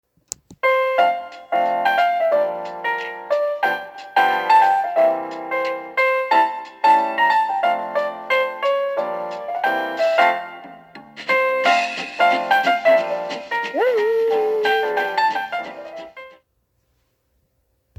Santa plays piano version of "We Wish You a Merry Christmas" and dog howls.